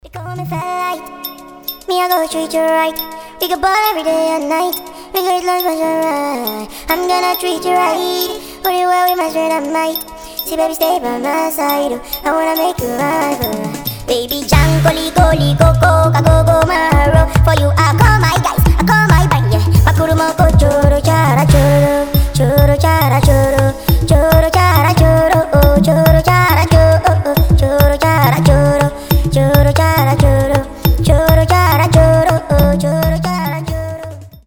мелодичные
африканские
ремиксы